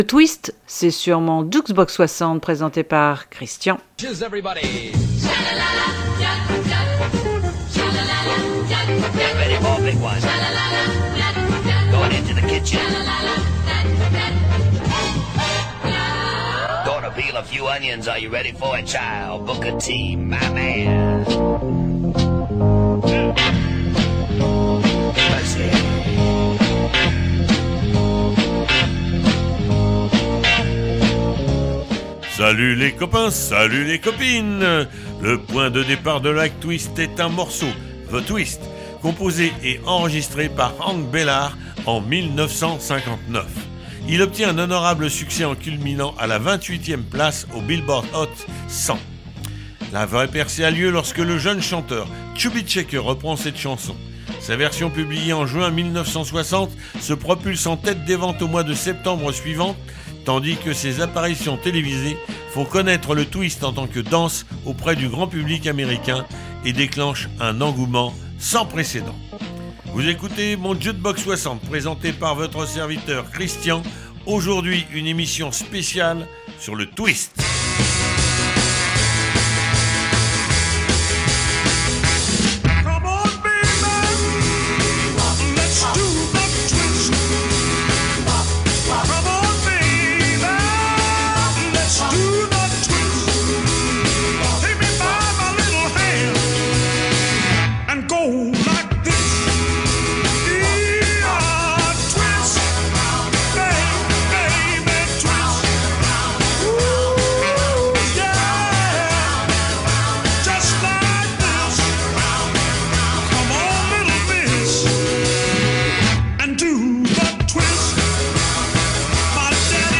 Émission spéciale Twist